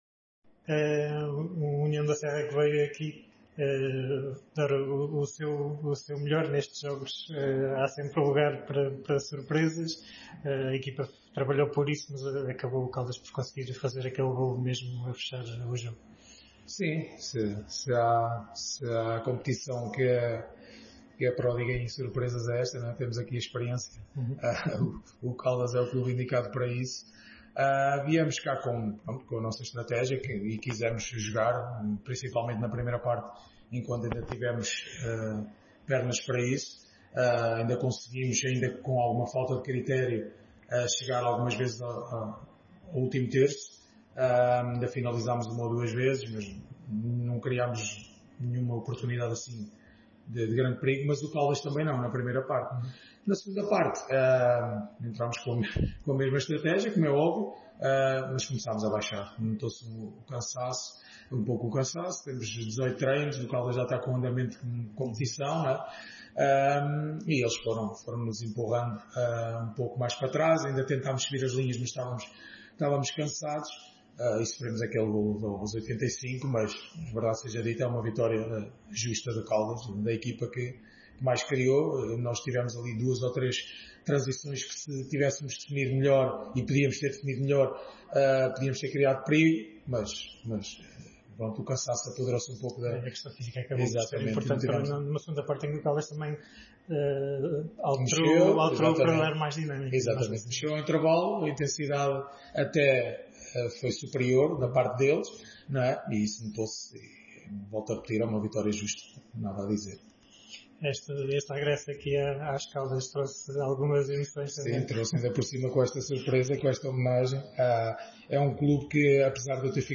no final do encontro